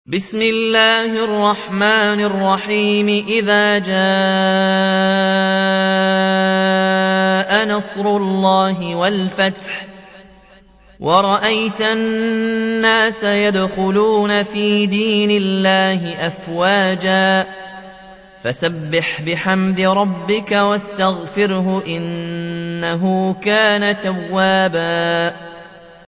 110. سورة النصر / القارئ